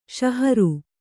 ♪ ṣaharu